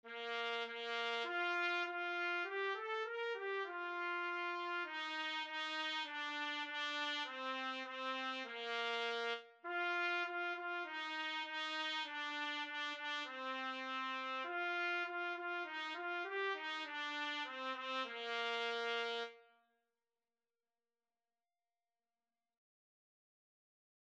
4/4 (View more 4/4 Music)
Moderato
Bb4-Bb5
Bb major (Sounding Pitch) C major (Trumpet in Bb) (View more Bb major Music for Trumpet )
Instrument:
Trumpet  (View more Beginners Trumpet Music)
Traditional (View more Traditional Trumpet Music)